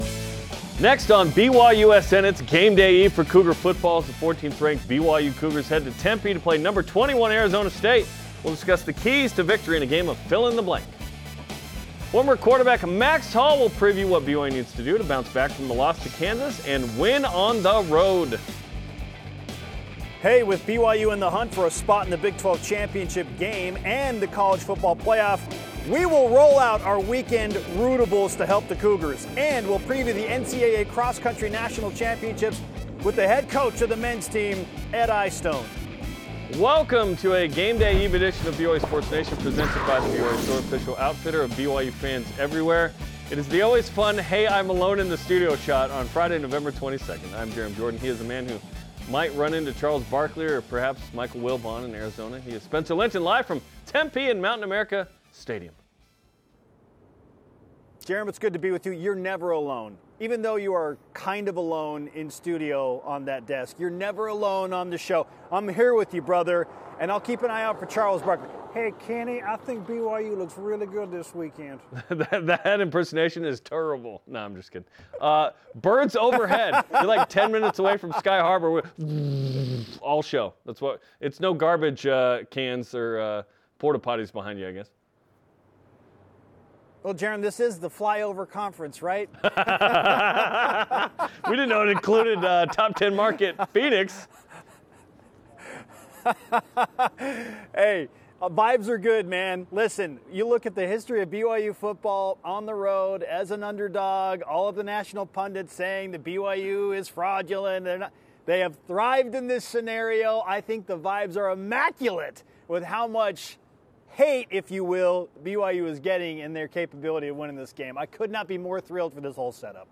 in-depth analysis, engaging discussion, interviews, and opinions with a national perspective on all things BYU Sports Nation